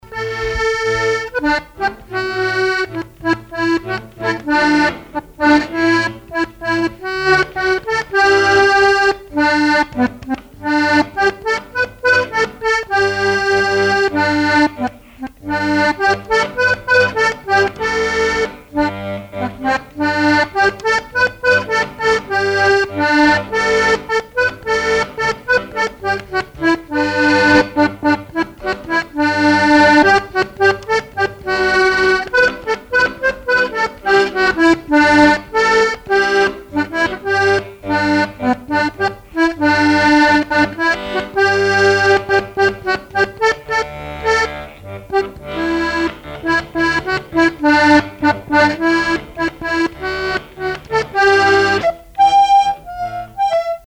Genre strophique
Chansons et répertoire du musicien sur accordéon chromatique
Pièce musicale inédite